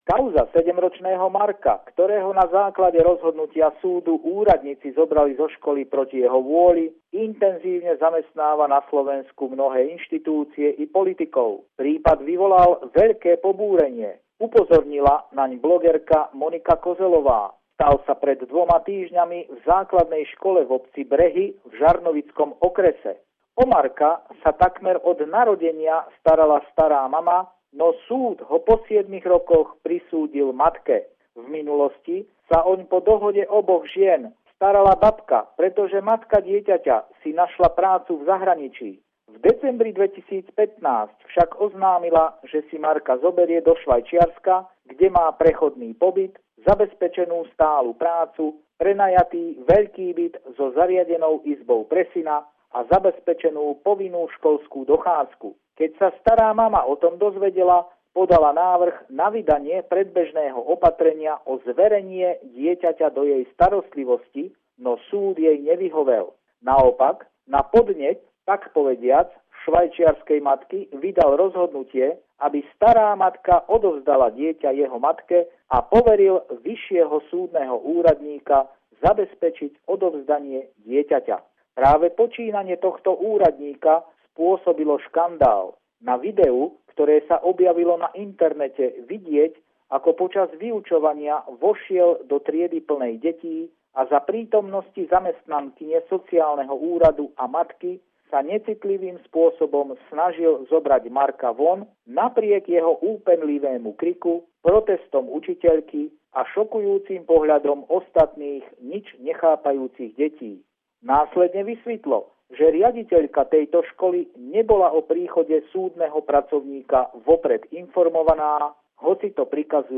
Pravidelný telefonát týždňa z Bratislavy